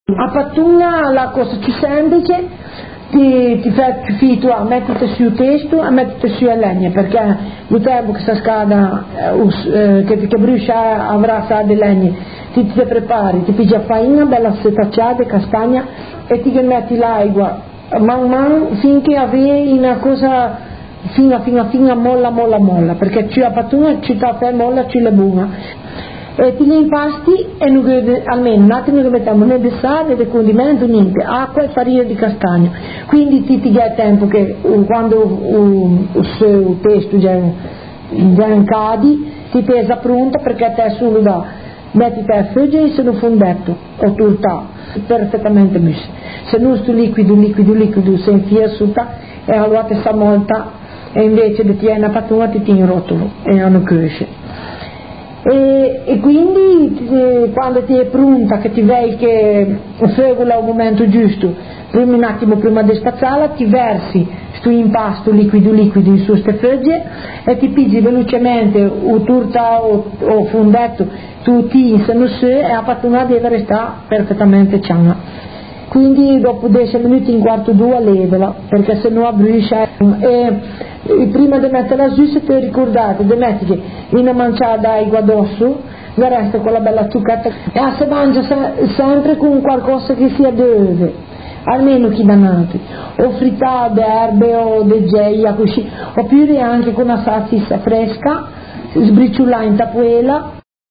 Trascrizione intervista del 01/12/2001